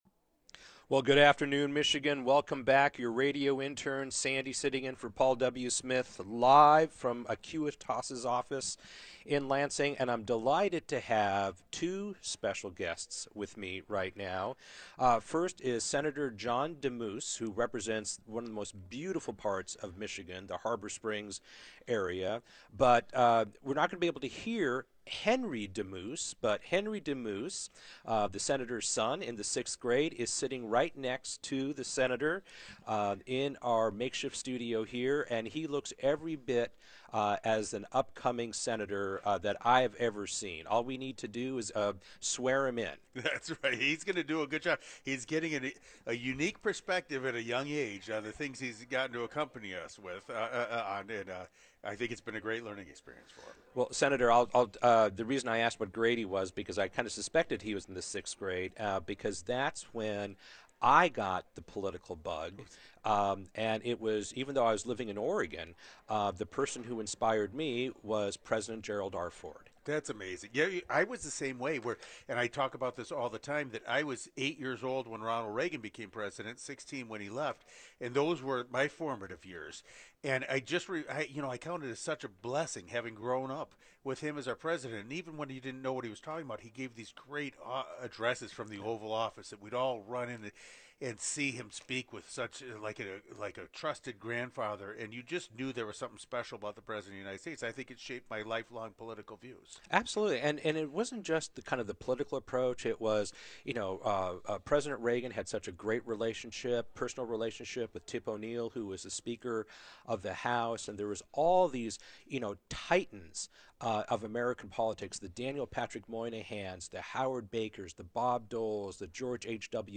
interviewing several prominent leaders in the Detroit Region as they awaited Governor Gretchen Whitmer’s final State of the State address.